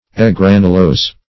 Search Result for " egranulose" : The Collaborative International Dictionary of English v.0.48: Egranulose \E*gran"u*lose`\, a. [Pref. e- + granule.]